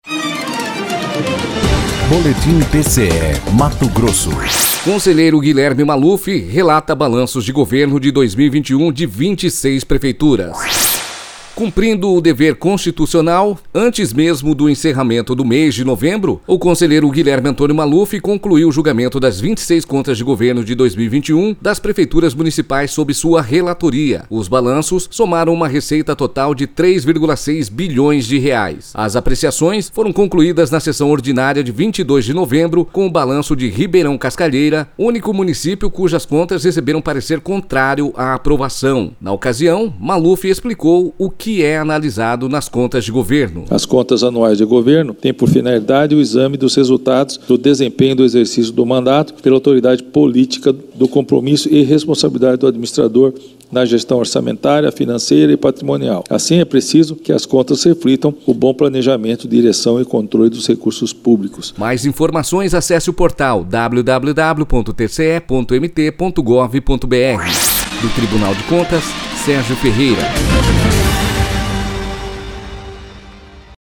Sonora: Guilherme Antonio Maluf – conselheiro do TCE-MT